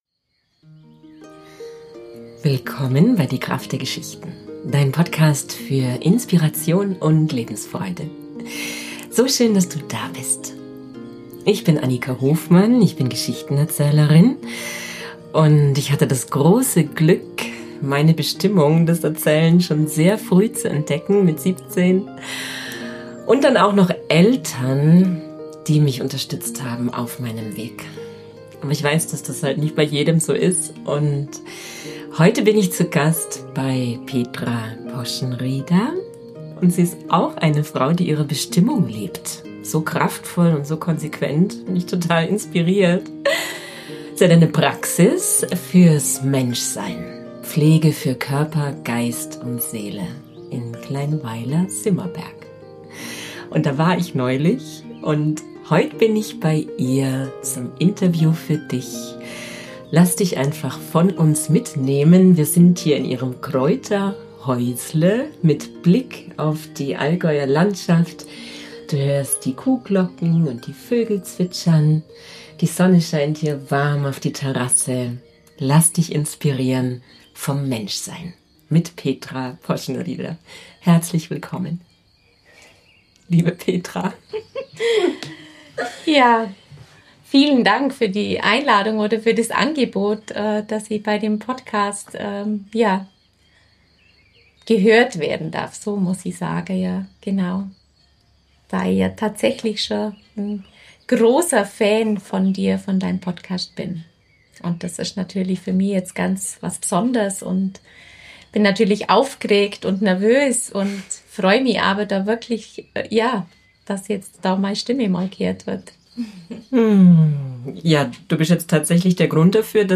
Heute habe ich so ein inspirierendes Interview für Dich.